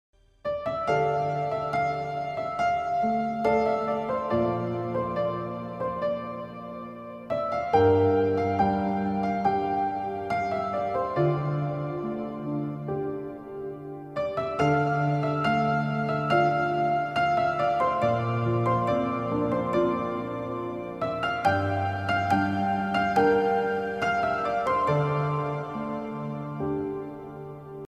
Sad Ringtone Music